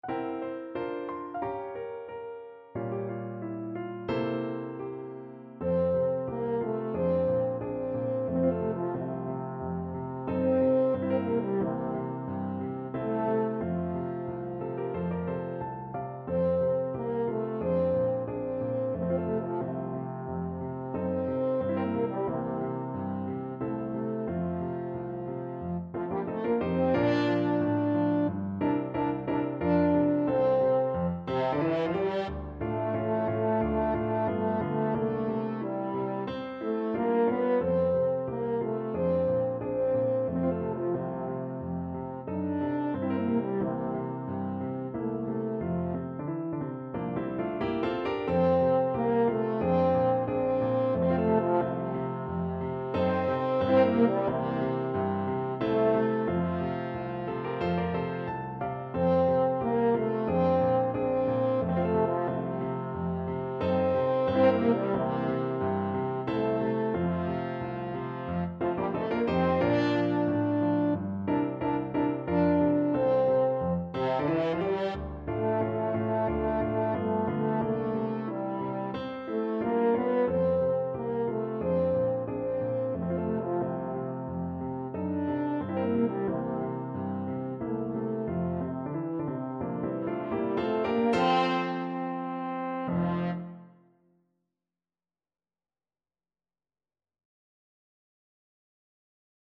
Grazioso =90
2/2 (View more 2/2 Music)
Classical (View more Classical French Horn Music)